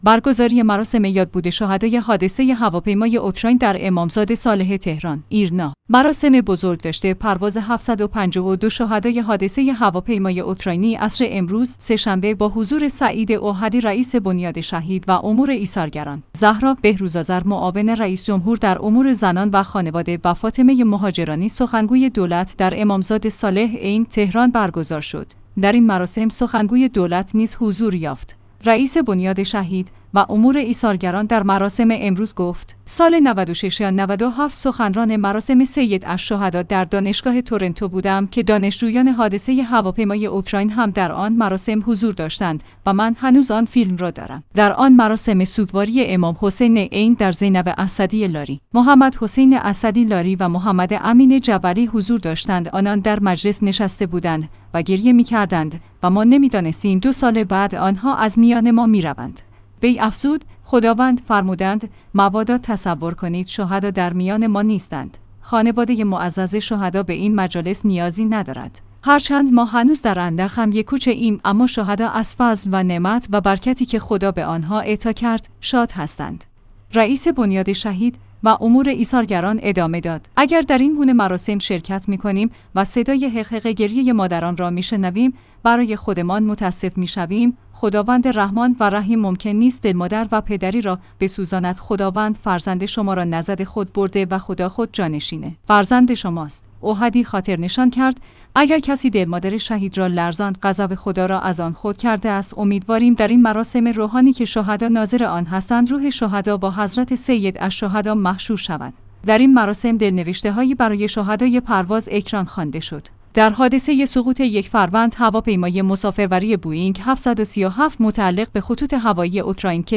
برگزاری مراسم یادبود شهدای حادثه هواپیمای اوکراین در امامزاده صالح تهران